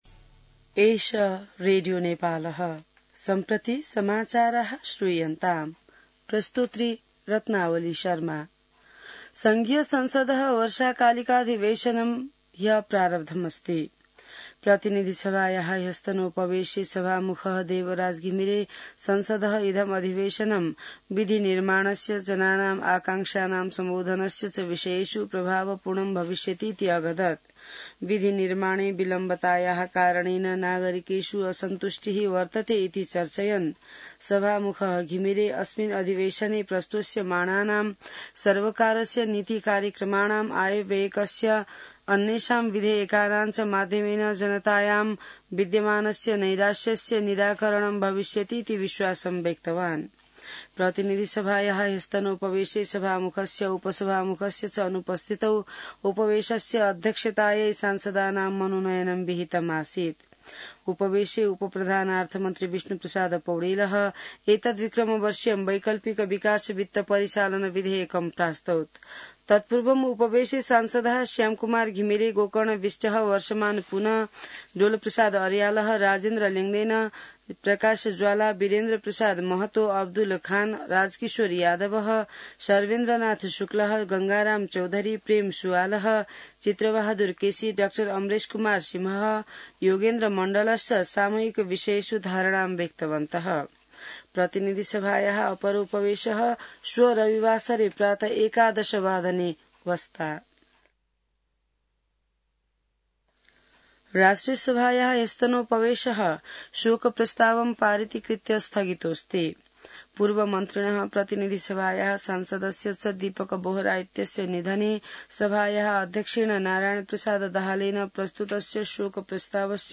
An online outlet of Nepal's national radio broadcaster
संस्कृत समाचार : १३ वैशाख , २०८२